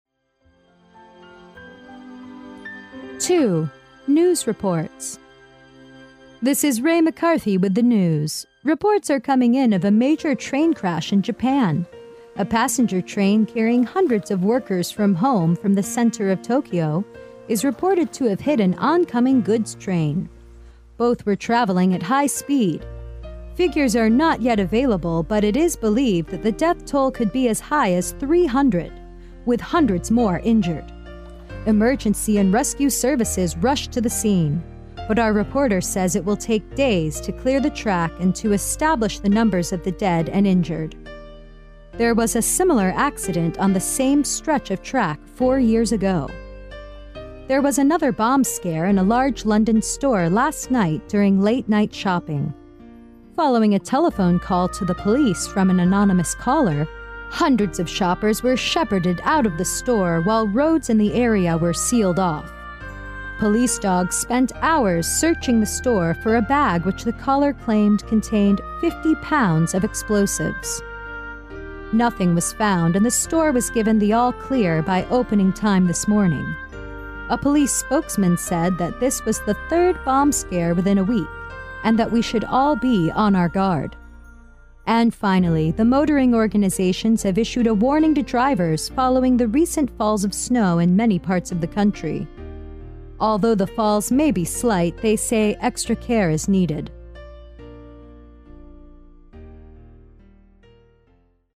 英语听力小短文走遍全世界18: News Reports 新闻报道（双语+mp3下载）
02newsreports.mp3